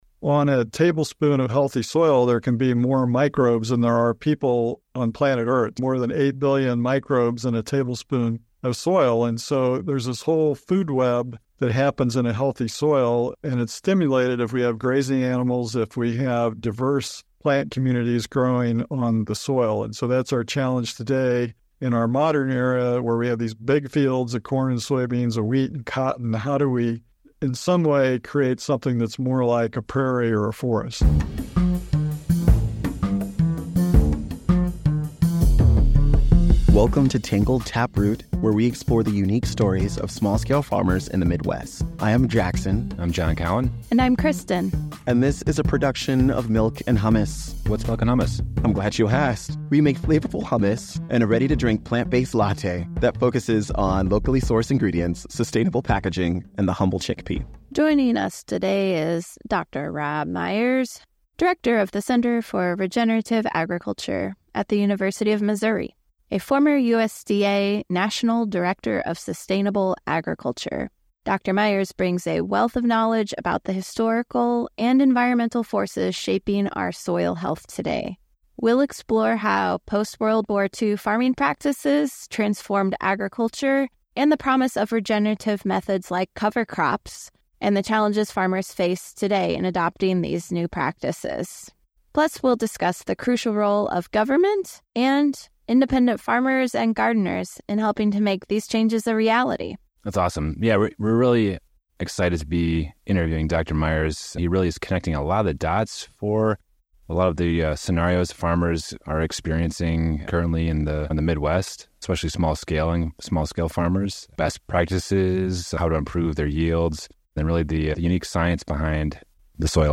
The conversation explores the burgeoning world of regenerative farming and soil health.